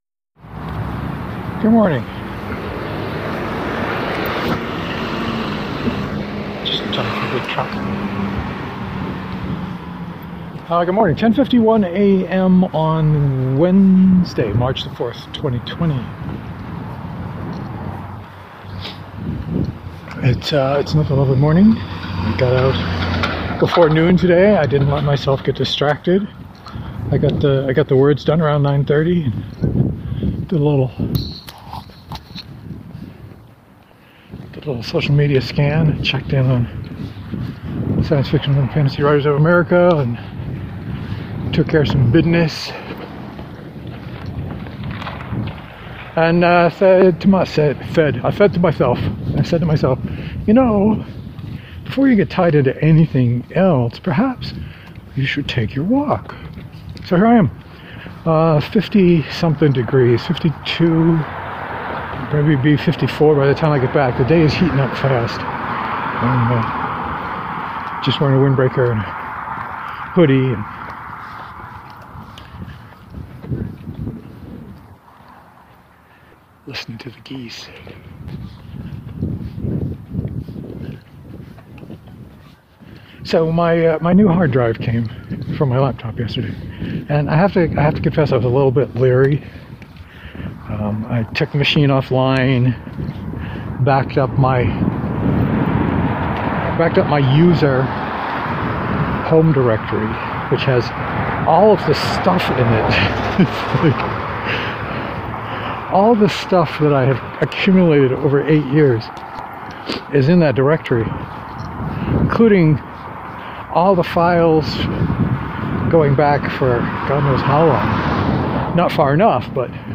Let me know if the audio is messed up.